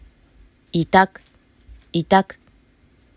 イタㇰ               　　itak         言葉